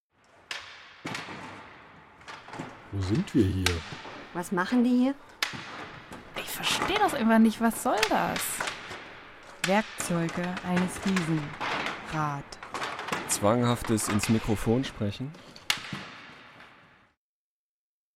Gleichzeitig lief in der restaurierten Taufkapelle unser Hörspiel ‚Zwischen allen Stühlen‘, das auch zum Leipziger Hörspielsommer eingereicht wurde. In einer Collage aus 12 kurzen Szenen geht es um die kleinen und großen Wahrheiten des Alltags und manchmal auch einfach nur um die übermächtige Aufgabe, ein Kirchenschiff von 700 Klappstühlen frei zu räumen.